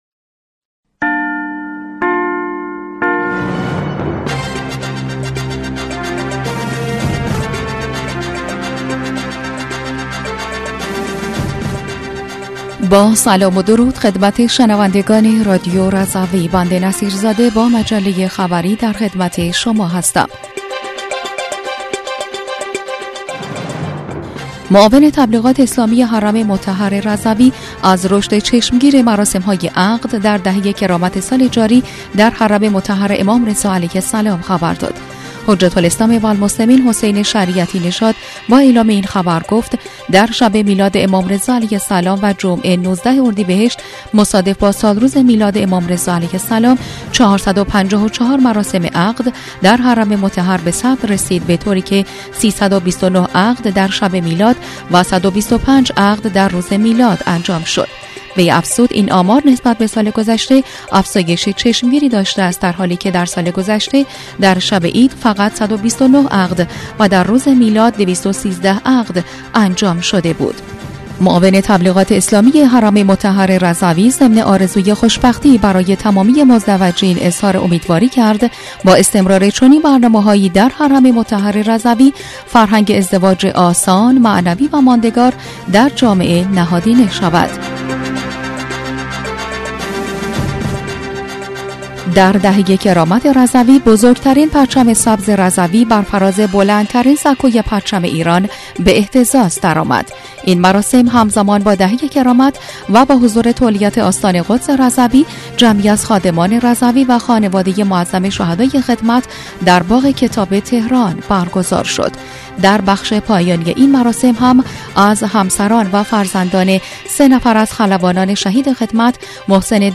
بسته خبری ۲۳ اردیبهشت‌ماه رادیو رضوی/